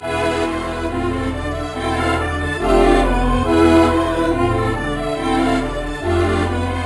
Gamer World Melody Loop 3.wav